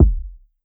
kick 1.wav